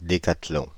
Ääntäminen
Ääntäminen Paris Tuntematon aksentti: IPA: /de.ka.tlɔ̃/ Haettu sana löytyi näillä lähdekielillä: ranska Käännös 1. десетобой {m} Suku: m .